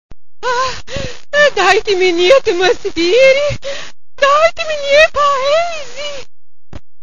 Festa di fine corsi
In occasione della classica festa di conclusione dell' anno sociale, ben due allestimenti "plen air" nel cortile di Palazzo Rinuccini: